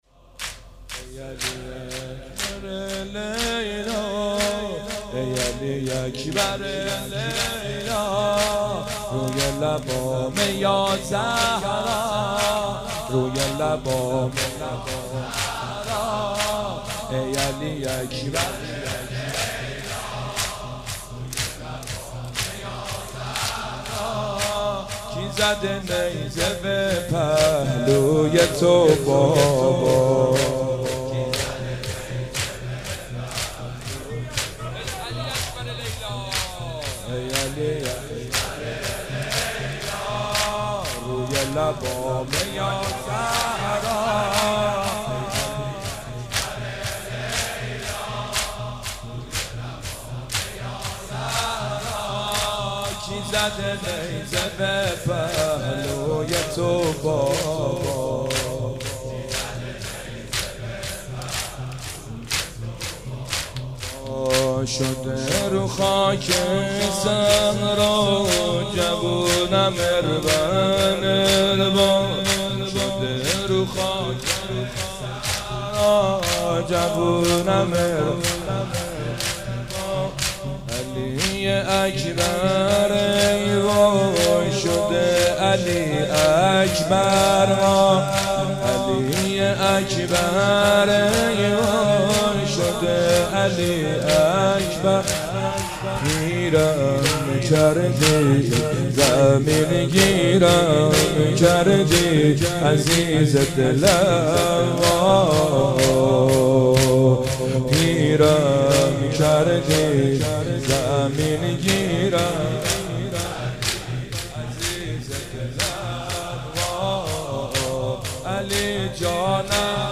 محرم 96 - زمینه - ای علی کربلا لیلا